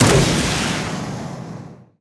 flowerrocket_01.wav